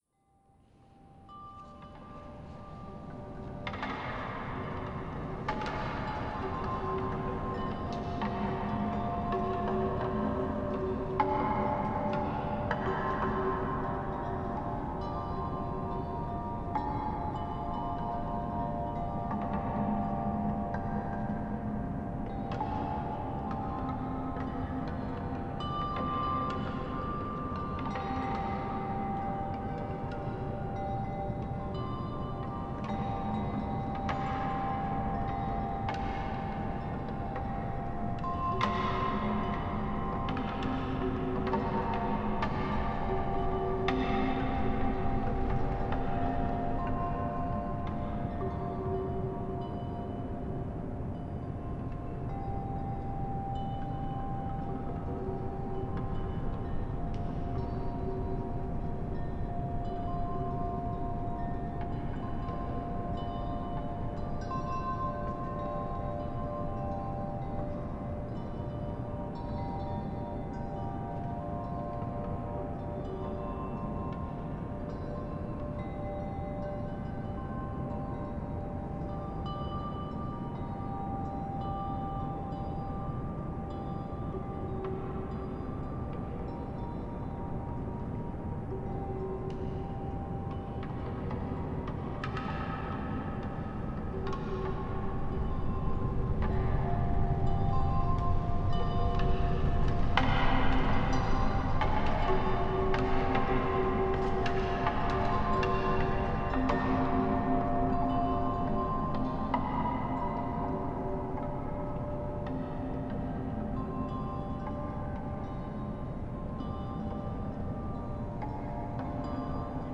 Transformed wind chimes: Nature-Symphony 10 Sound Effect — Free Download | Funny Sound Effects